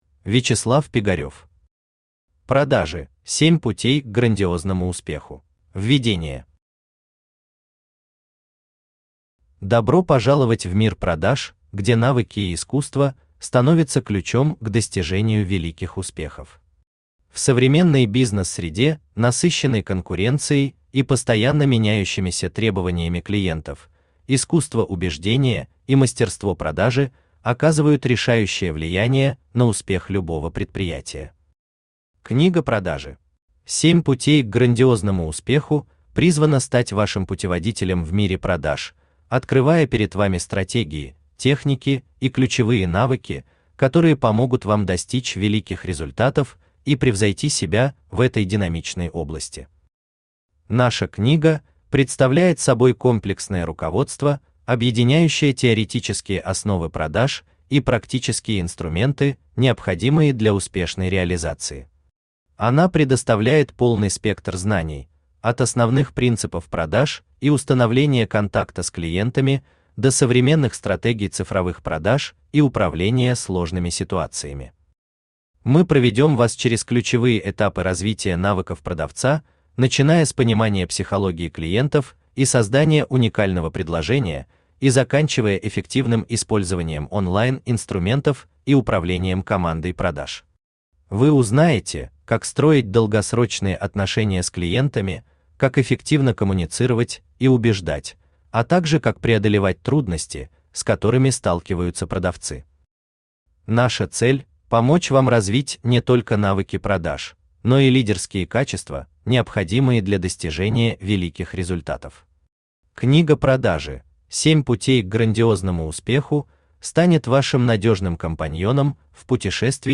Аудиокнига Продажи: 7 путей к грандиозному успеху | Библиотека аудиокниг
Aудиокнига Продажи: 7 путей к грандиозному успеху Автор Вячеслав Пигарев Читает аудиокнигу Авточтец ЛитРес.